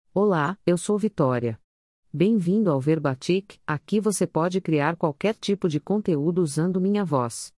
VitóriaFemale Brazilian Portuguese AI voice
Vitória is a female AI voice for Brazilian Portuguese.
Voice sample
Listen to Vitória's female Brazilian Portuguese voice.
Vitória delivers clear pronunciation with authentic Brazilian Portuguese intonation, making your content sound professionally produced.